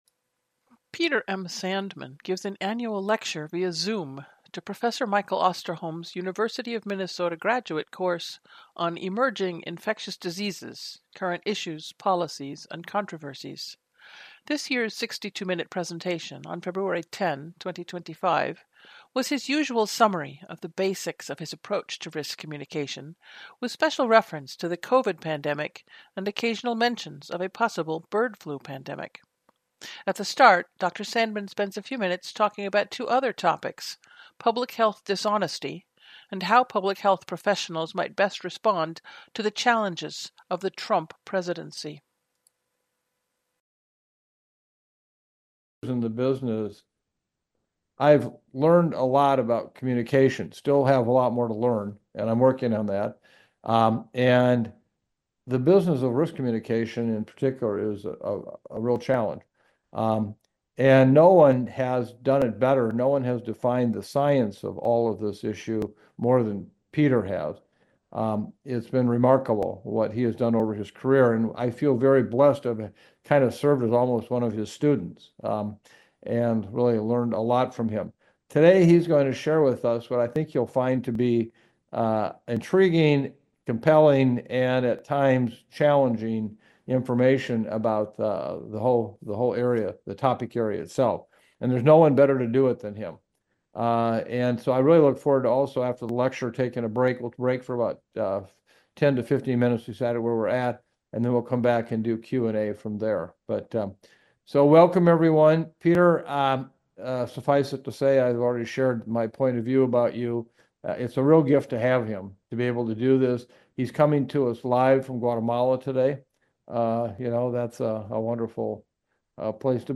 Presentation via Zoom to the Council of State and Territorial Epidemiologists, January 11, 2022